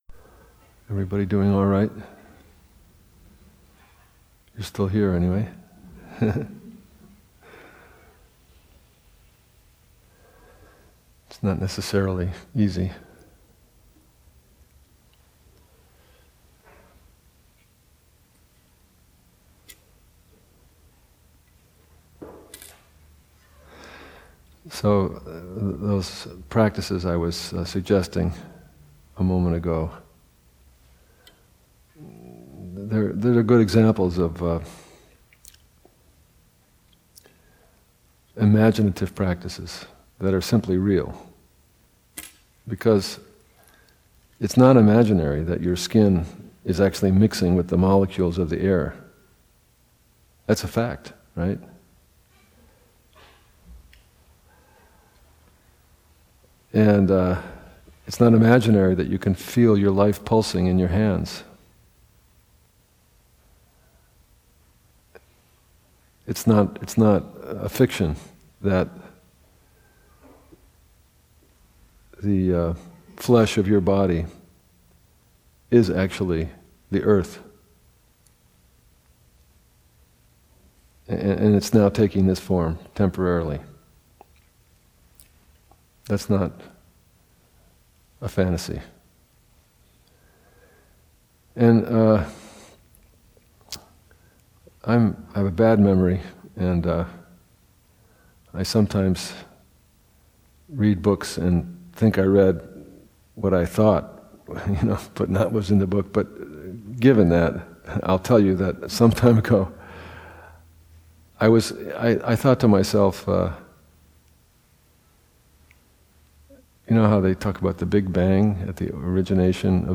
Teacher: Zoketsu Norman Fischer Date: 2015-09-19 Venue: Seattle Insight Meditation Center